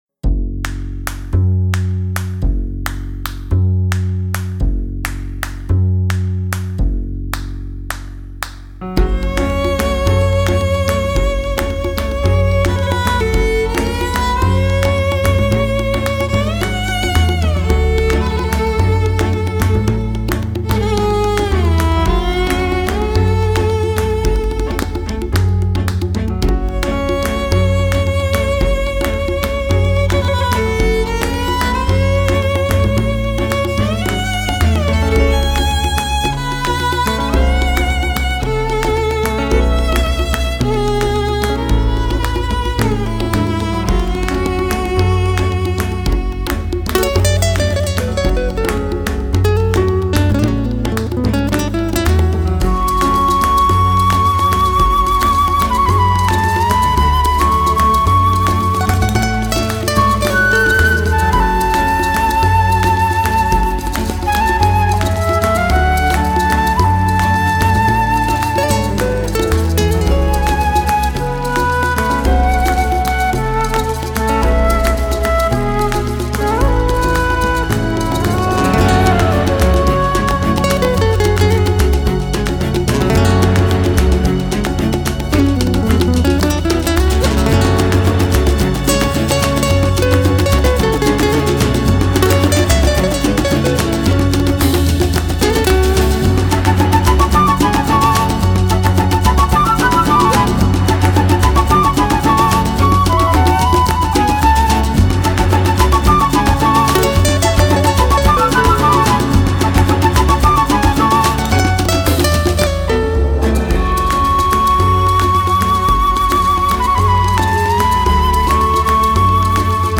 新世紀長笛
神秘人声的萦绕忽隐忽现